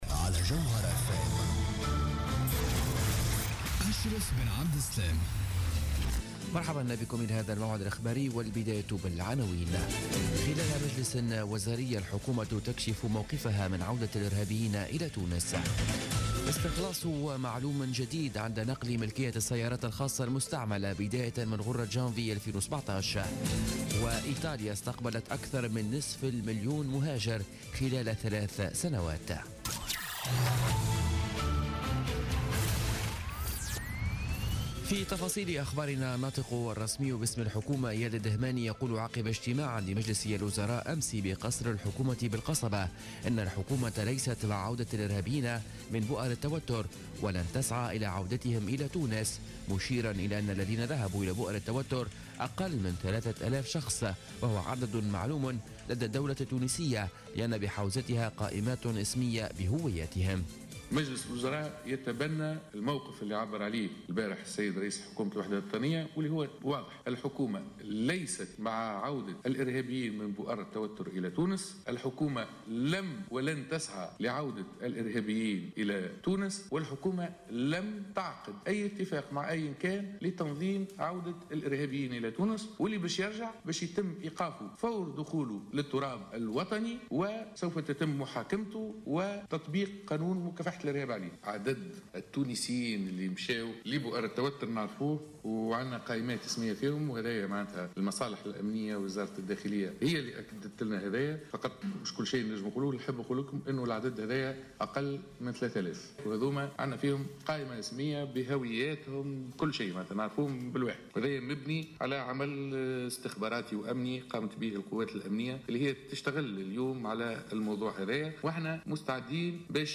نشرة أخبار منتصف الليل ليوم السبت31 ديسمبر 2016